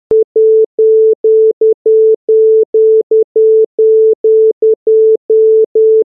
Tones and silence for testing brief loss of lock on external DAC